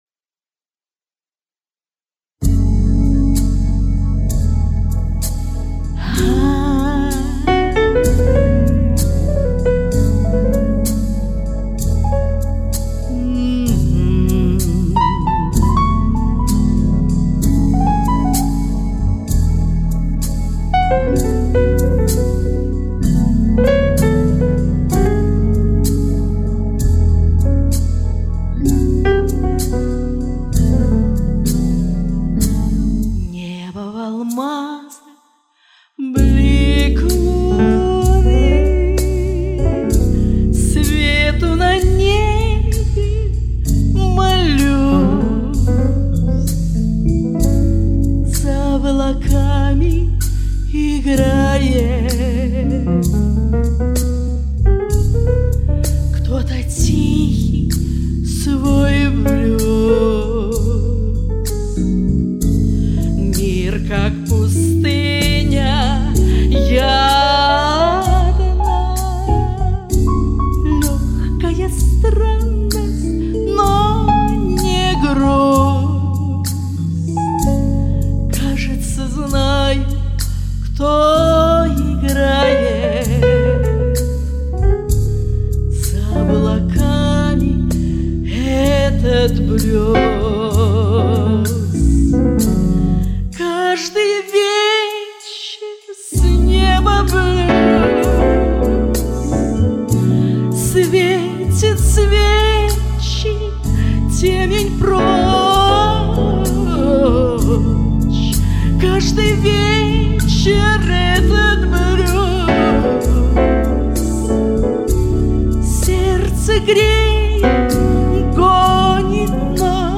более задумчивая, мелодичная версия